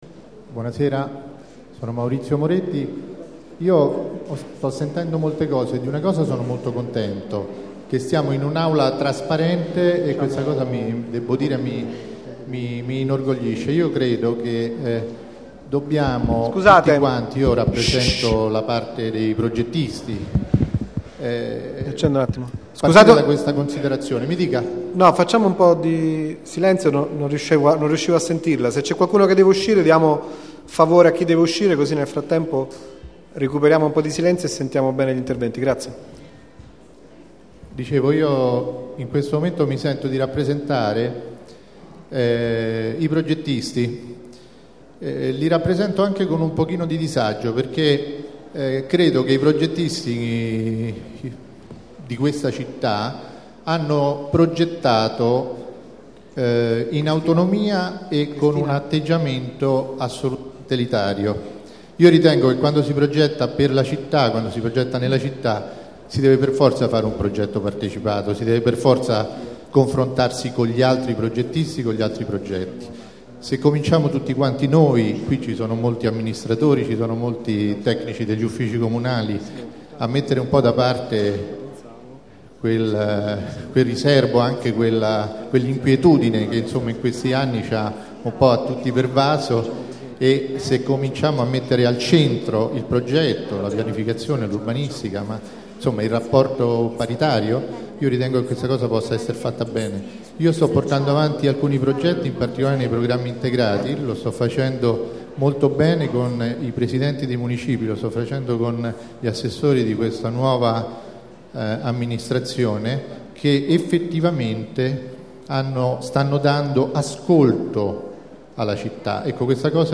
Registrazione integrale dell'incontro svoltosi il 19 dicembre 2014 presso la Casa della Città, in P.za Da Verrazzano, 7.